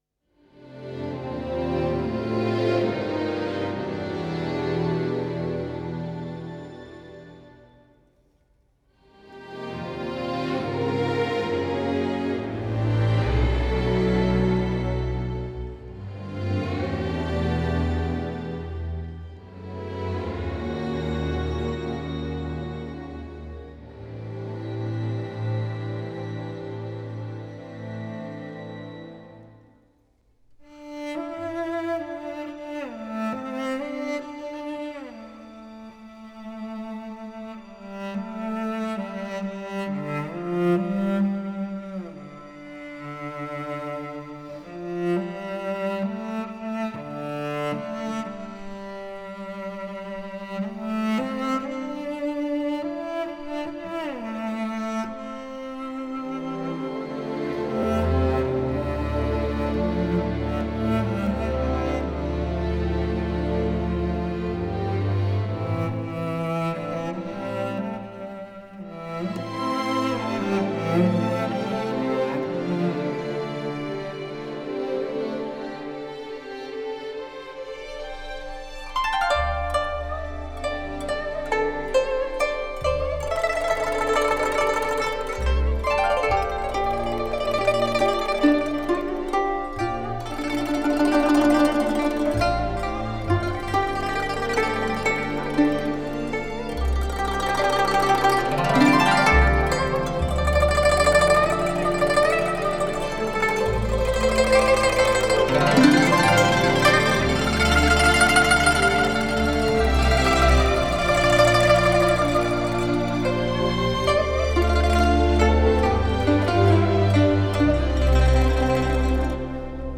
前所未有的民族交响与发烧人声于中央电视台一号录音棚交融，经典的韵味与现代的技术。
女声主唱
男声独唱
古筝
笛子、箫
琵琶
二胡
录音棚：中央电视台一号棚